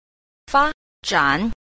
9. 發展 – fāzhǎn – phát triển